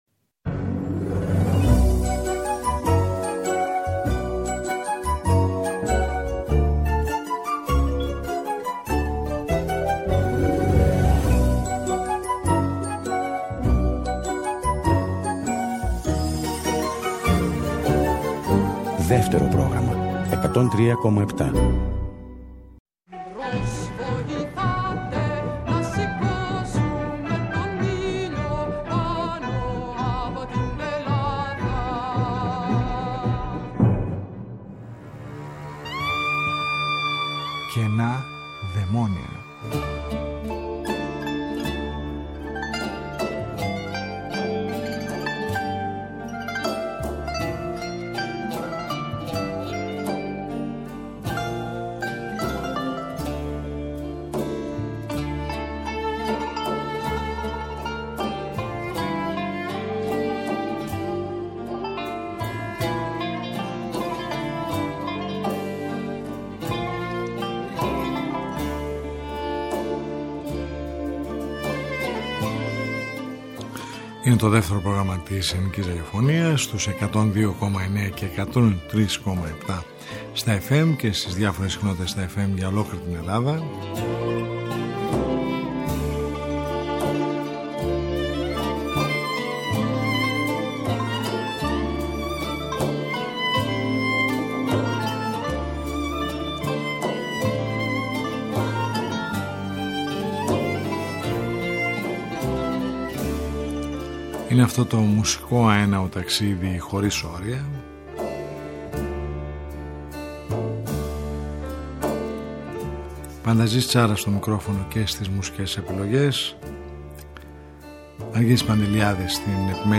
Μια ραδιοφωνική συνάντηση κάθε Σάββατο στις 10:00 που μας οδηγεί μέσα από τους ήχους της ελληνικής δισκογραφίας του χθες και του σήμερα σε ένα αέναο μουσικό ταξίδι.